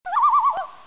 loon.mp3